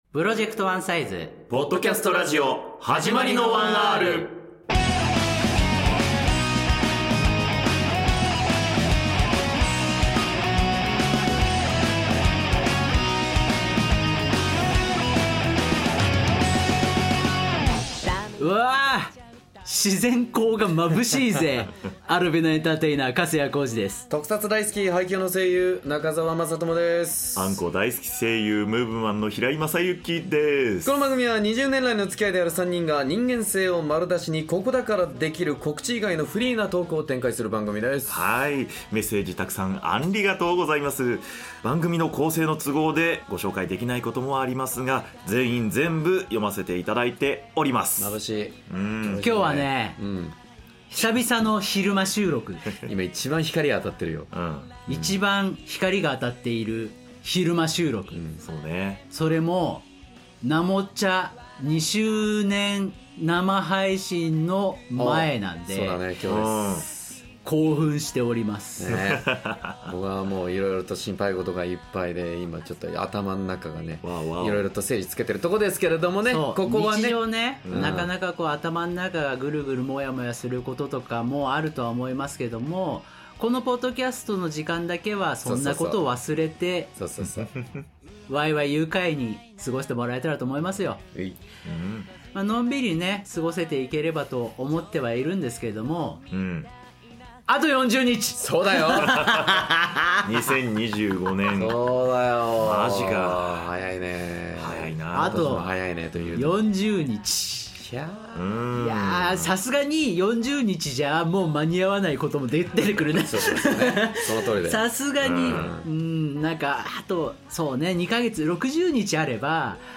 今回は…なもちゃんねる２周年祭の日の昼間に収録でしたカーテンを開けて自然光が差し込む中での収録は珍しいのです～